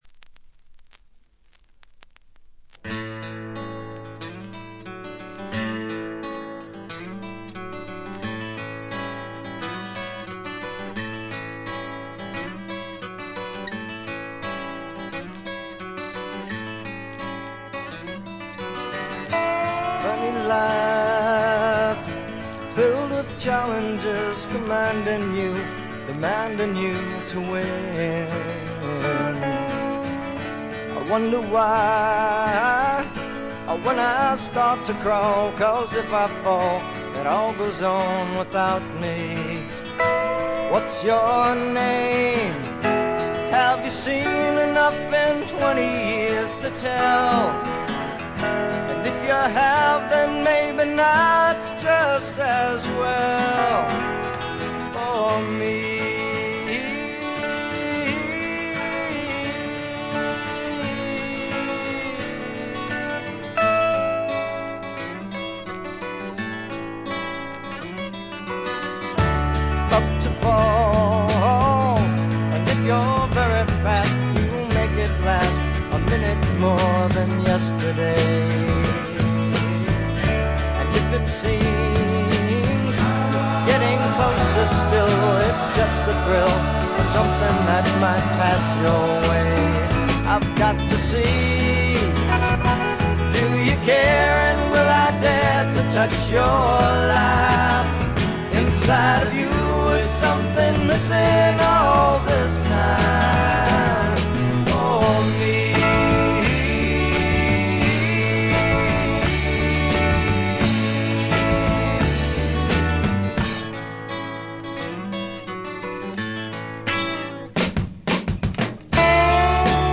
静音部でのバックグラウンドノイズや軽微なチリプチはありますが、気になるようなノイズはないと思います。
60年代的なサイケ要素がところどころで顔を出します。
試聴曲は現品からの取り込み音源です。
Recorded At - Record Plant, Los Angeles